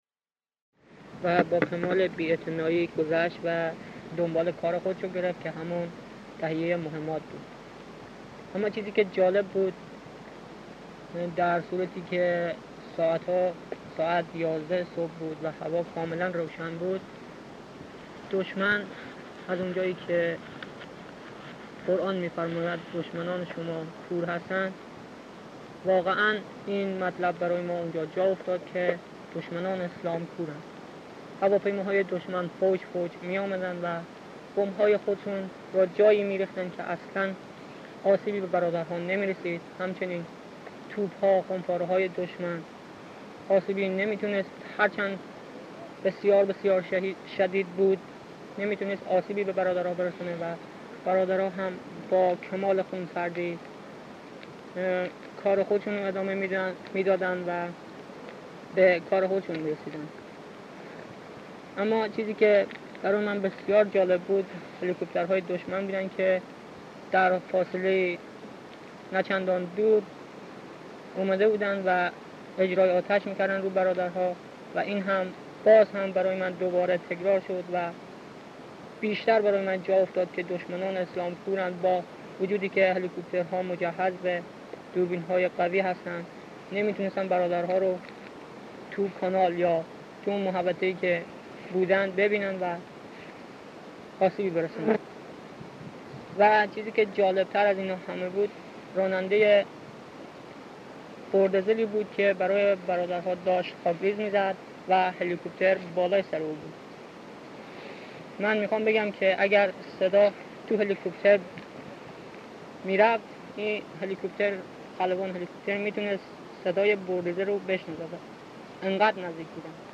توضیح: به دلیل قدیمی بودن فرمت ضبط صدا، تفکیک مصاحبه ها امکان پذیر نیست